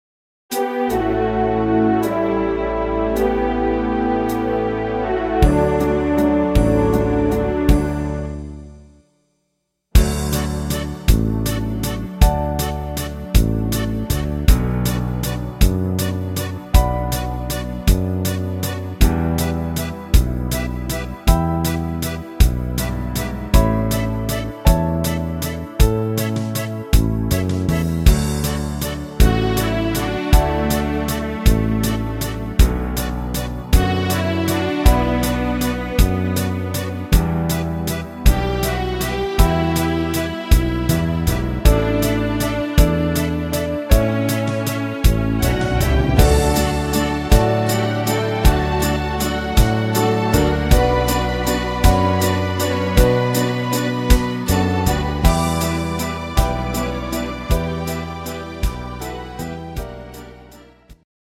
schöner Walzer